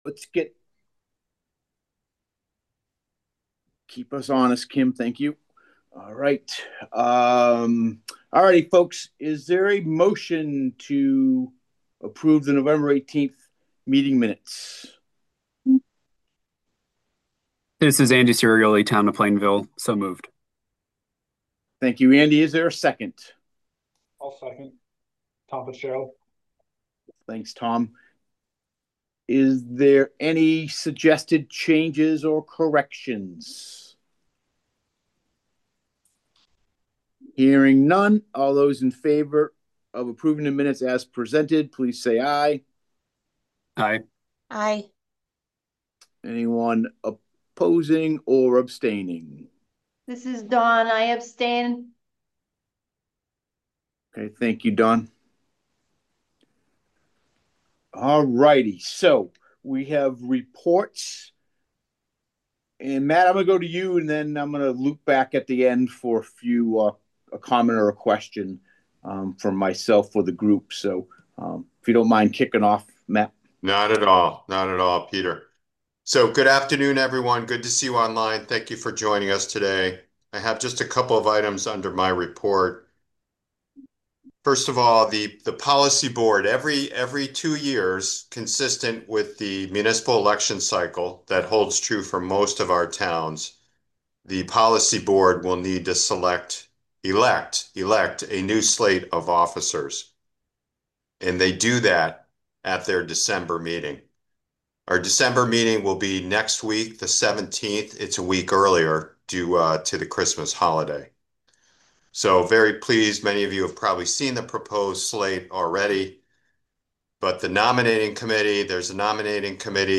*Meeting access is remote only, consistent with provisions specified in Connecticut Public Act 22-3.